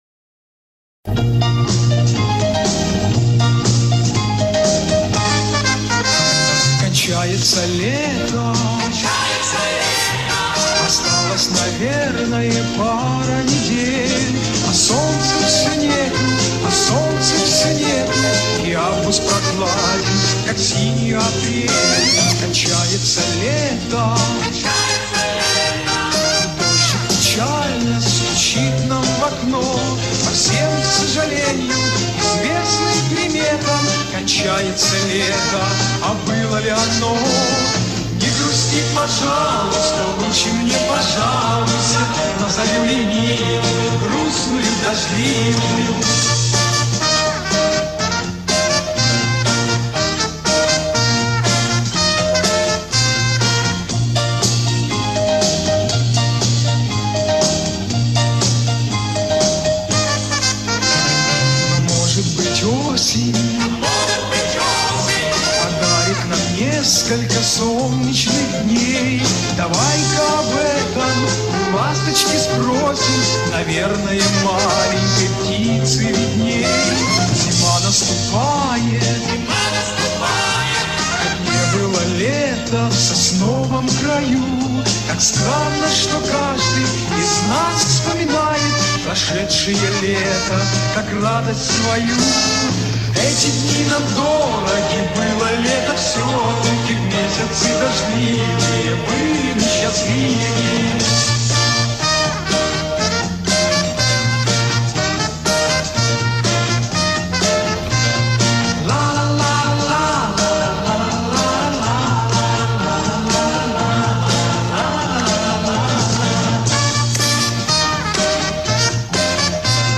Еще несколько реставраций студийных и живых записей